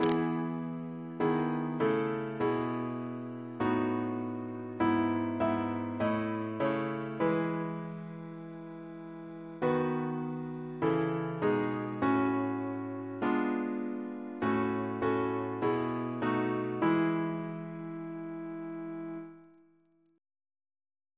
Double chant in E Composer: John Camidge, Jr. (1790-1859) Reference psalters: ACB: 160; ACP: 234; CWP: 29; RSCM: 27